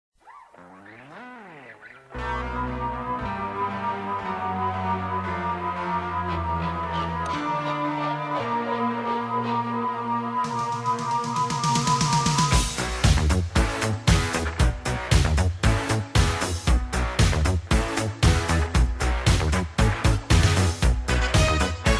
Саундтрек к фильму
музыка из кино